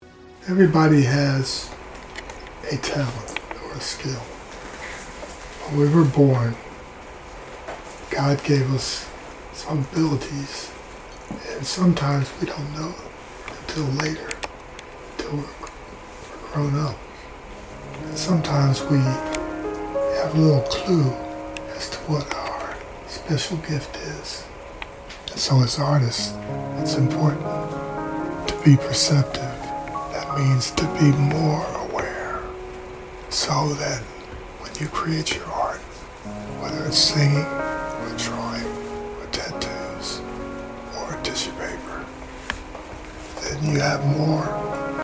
Voice Memo